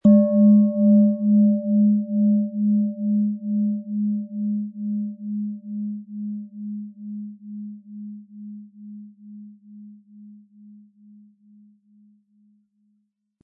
Planetenton 1
Im Audio-Player - Jetzt reinhören hören Sie genau den Original-Klang der angebotenen Schale. Wir haben versucht den Ton so authentisch wie machbar aufzunehmen, damit Sie gut wahrnehmen können, wie die Klangschale klingen wird.
Durch die traditionsreiche Fertigung hat die Schale vielmehr diesen kraftvollen Ton und das tiefe, innere Berühren der traditionellen Handarbeit
Lieferung inklusive passendem Klöppel, der gut zur Klangschale passt und diese sehr schön und wohlklingend ertönen lässt.
MaterialBronze